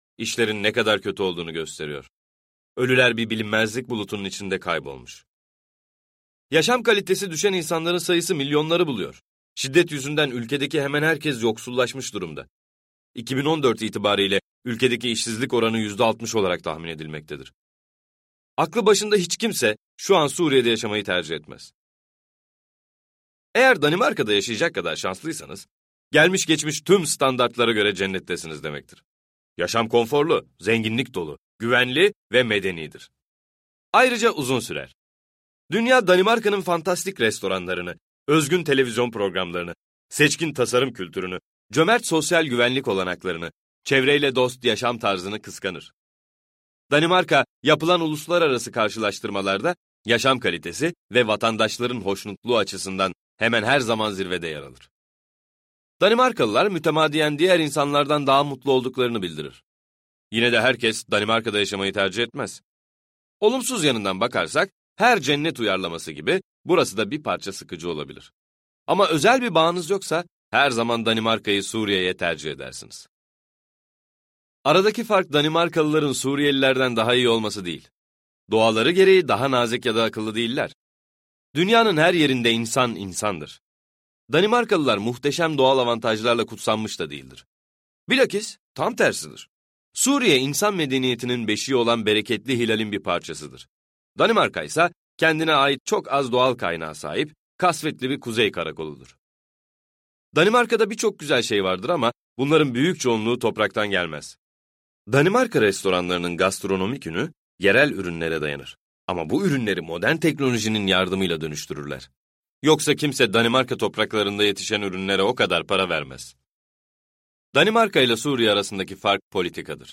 Politika - Seslenen Kitap